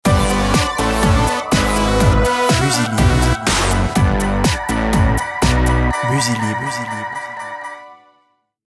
Dynamique, robotique, fun, sport, explosif, moderne